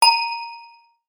radio.wav